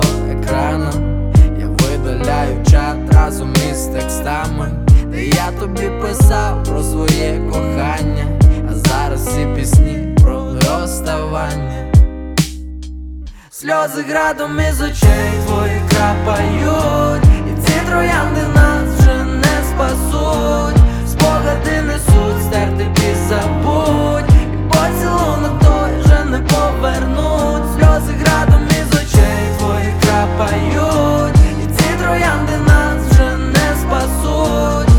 Жанр: Электроника / Русские